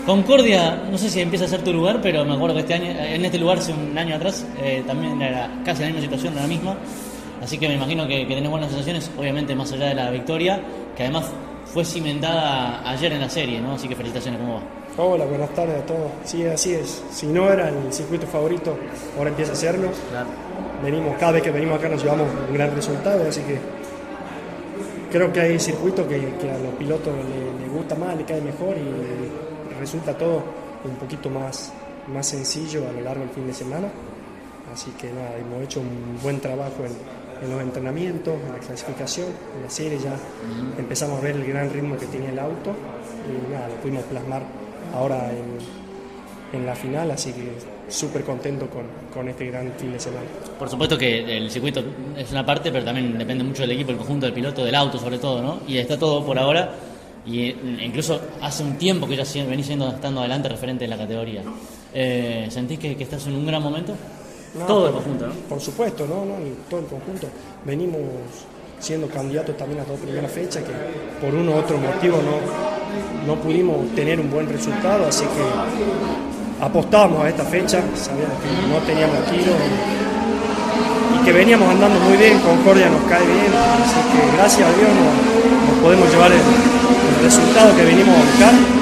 CÓRDOBA COMPETICIÓN estuvo presente en el evento y, al término de la competencia definitiva de la divisional menor, dialogó con cada uno de los protagonistas del podio, así como también del cordobés mejor ubicado al término de la prueba.